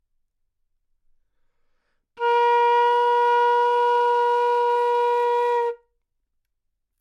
长笛单音 " 长笛 Asharp4
Tag: 好声音 单注 多样本 Asharp4 纽曼-U87 长笛